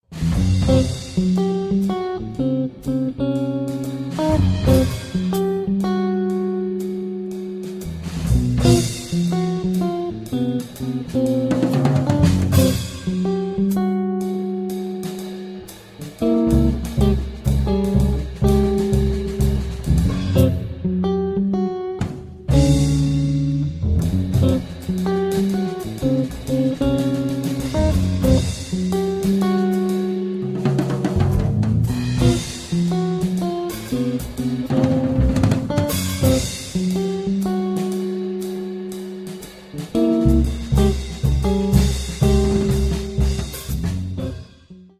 Contemporary Jazz Guitar meets Pitch Class Set Improvisation
Guitar
Bass
Drums